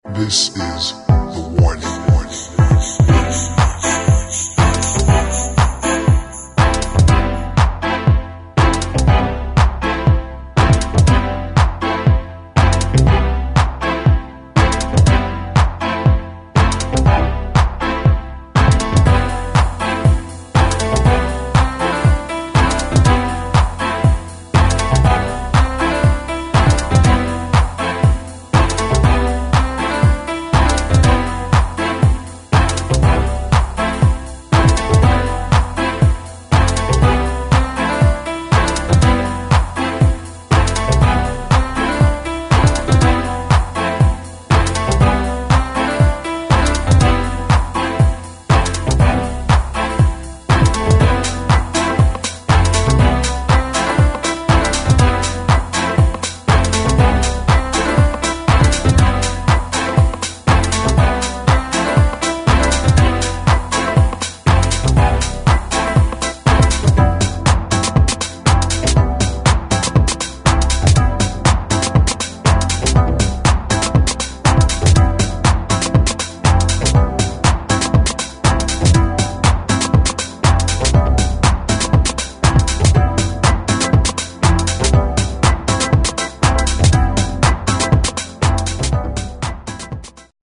[ HOUSE / DEEP HOUSE ]